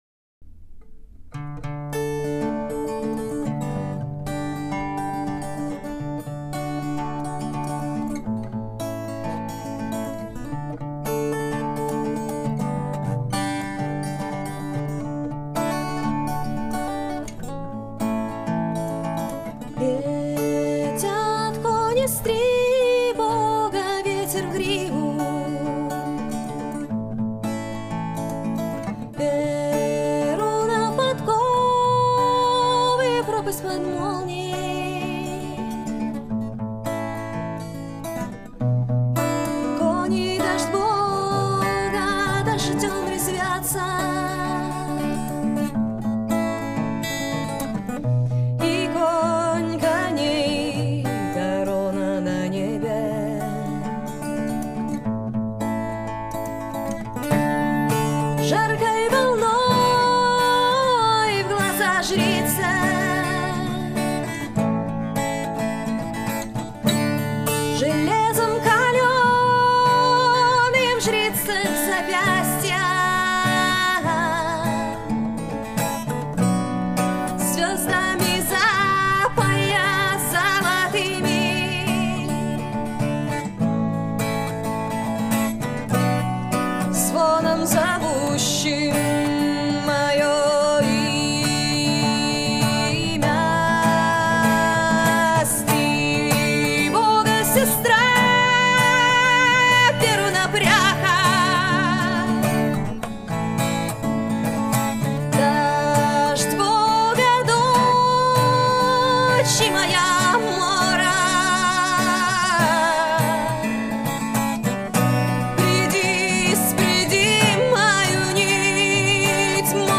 Фолк. Соло под гитару.